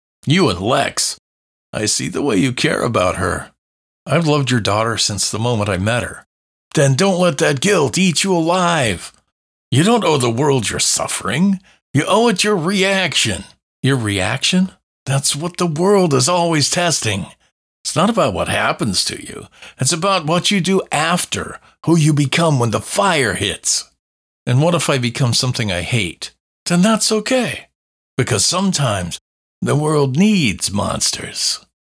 Dialog – 2 Characters
Dialog-2-Characters.mp3